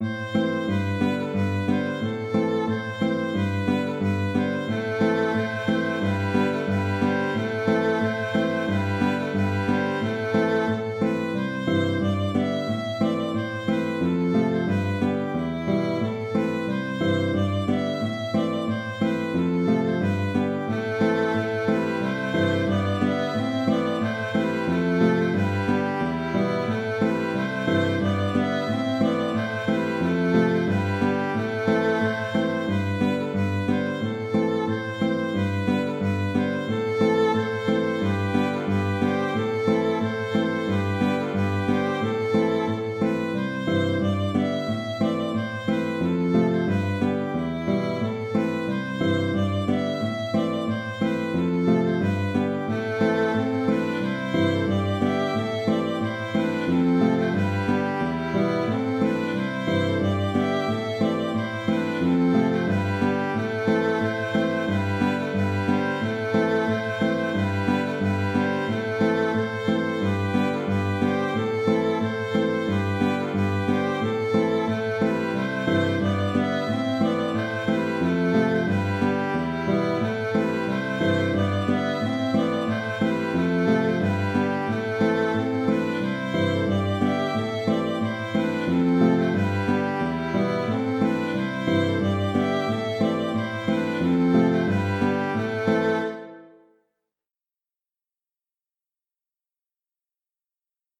Bonsoir Maître de Maison (An dro) - Musique bretonne
Cette chanson traditionnelle est chantée avec des interprétations un peu différente selon les groupes, c’est-à-dire avec ou sans reprises. Dans la version enregistrée que je propose, il y a toutes les reprises, mais il n’y a bien sûr aucune obligation.